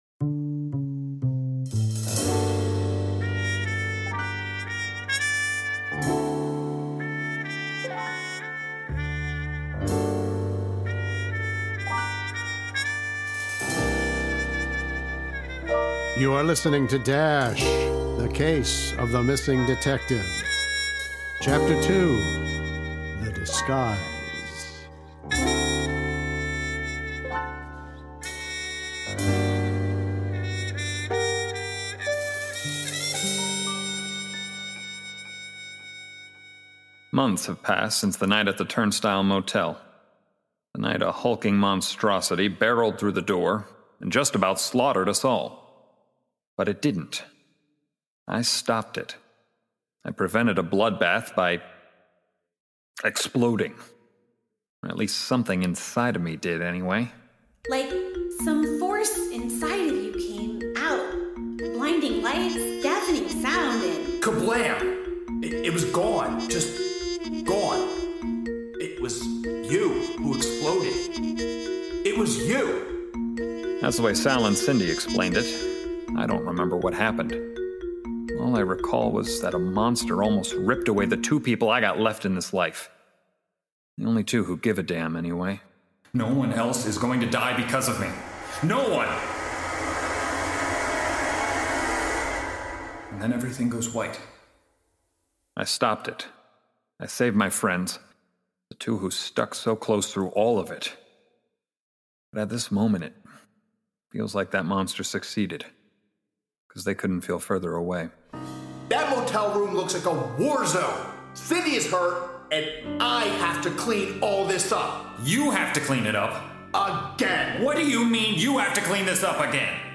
Ripped from the pages of the hit indie comic book, this audio drama is a reimagined queer take on a classic genre that’s a blend of noir, horror, comedy, and mystery.